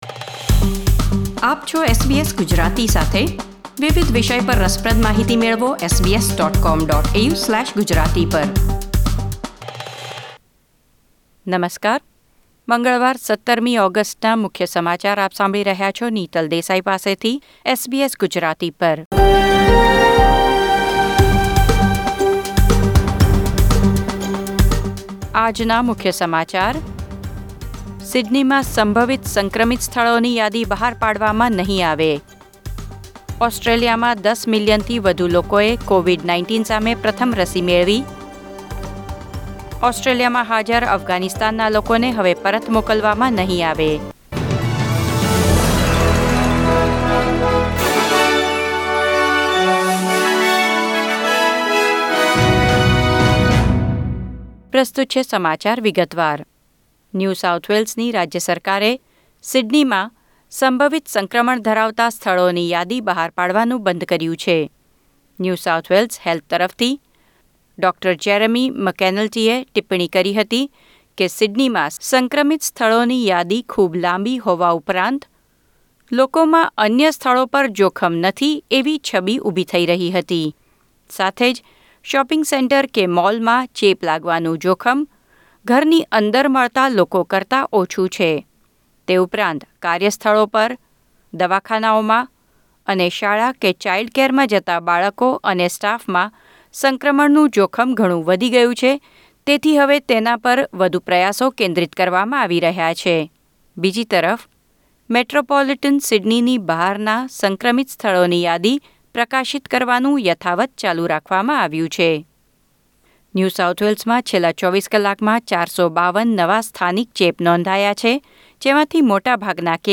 SBS Gujarati News Bulletin 17 August 2021